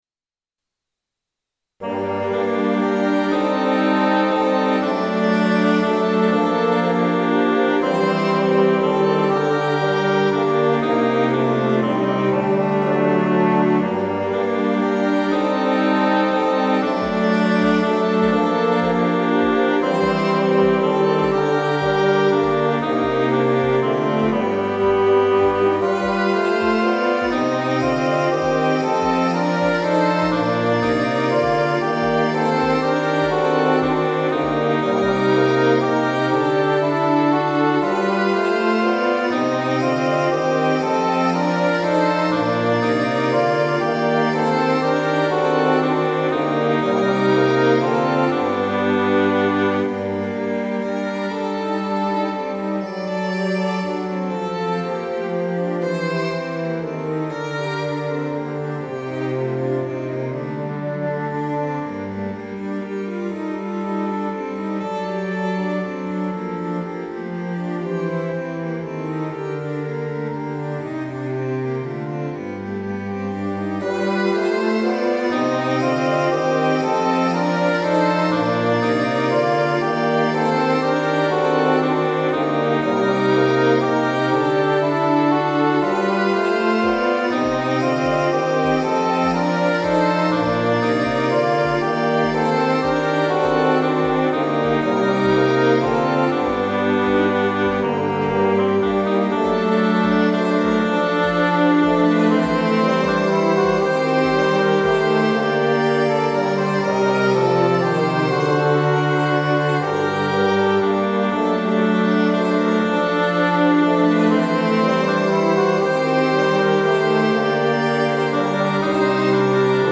▼DL↓   1.0 フルート オーボエ フレンチホルン ファゴット